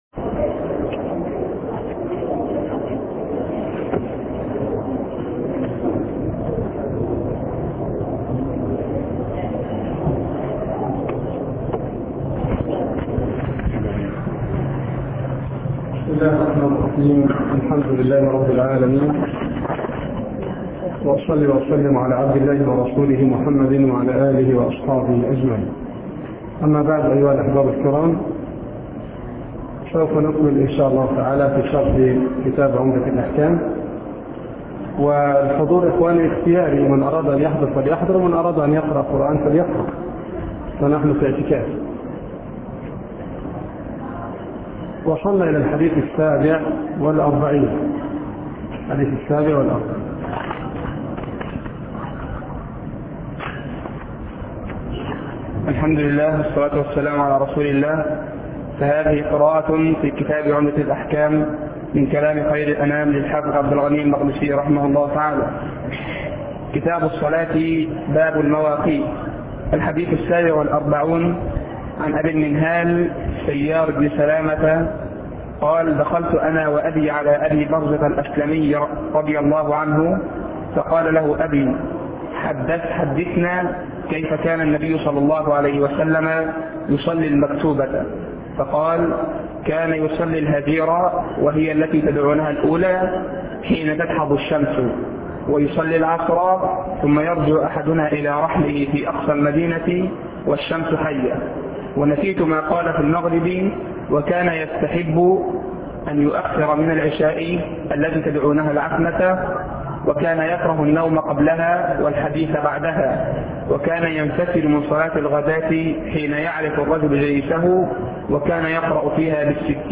شرح عمدة الأحكام (( الدرس الثالث